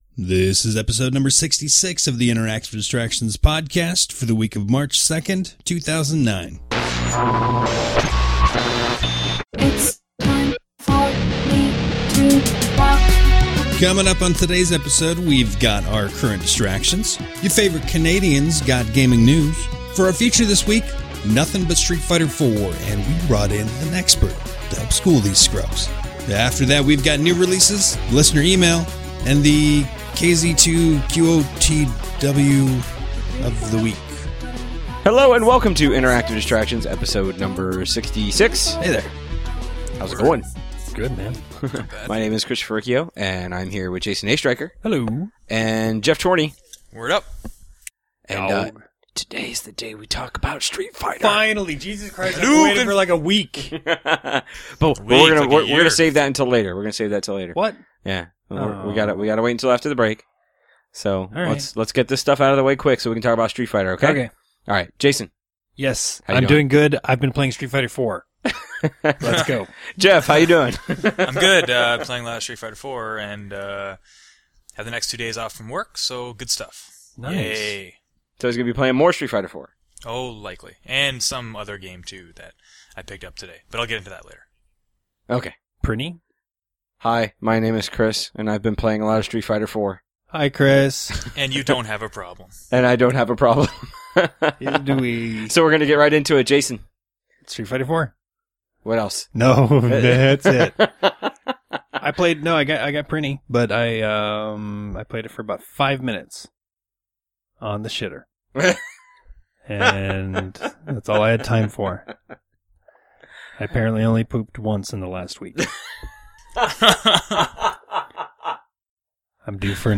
Then, our back up only had the input voices (those going into mics in the room… no skyped in voices) on it. So we had to rerecord the feature.